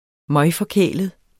Udtale [ ˈmʌjfʌˈkεˀləð ]